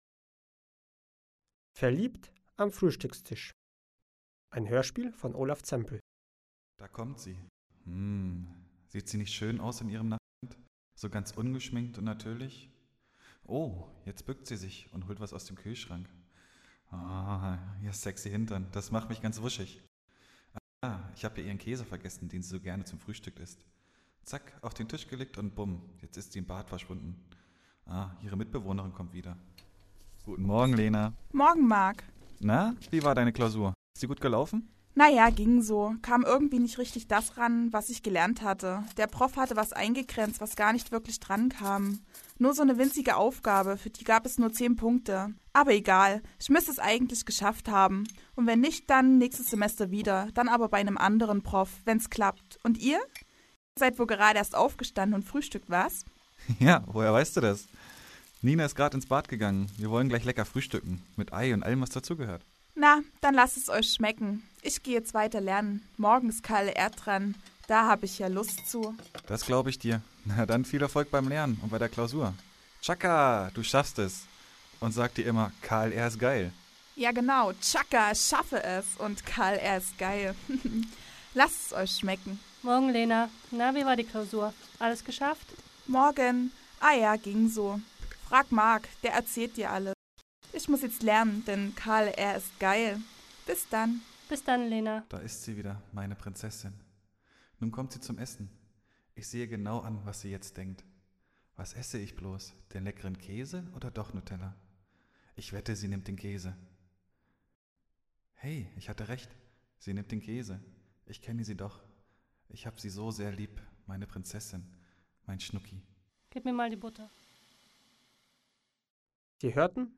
Unter anderem ein kleines Hörspiel sowie ein Usability Test
Hörspiel: Verliebt am Frühstückstisch (mp3)